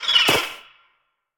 Sfx_creature_babypenguin_death_land_01.ogg